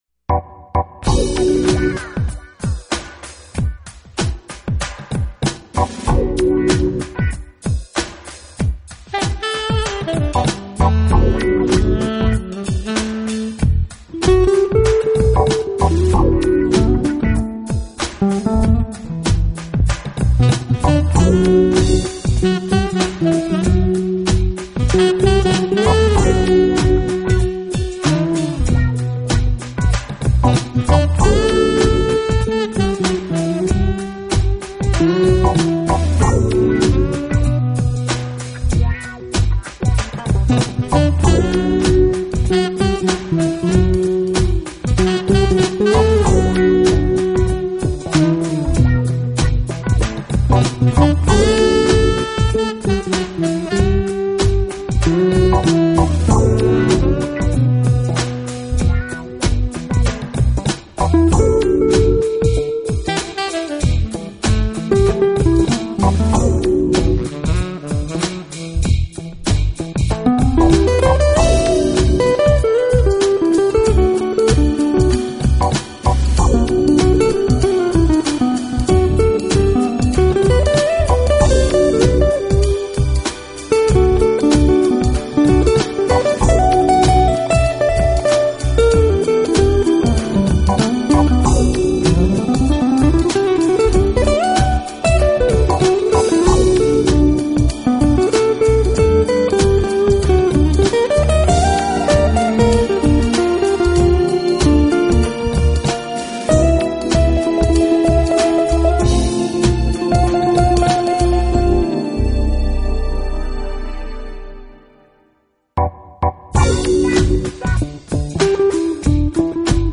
音乐类型：JAZZ
缓的吹奏，有如徜徉在慵懒的国度；手指不自觉地跟着跃动起来，配合着舒缓的Sax，
随性但又带着点规律的独奏飘散全场。
色乐章里，犹如编织出浪漫舒緩而又自由自在的现代雅痞夜生活。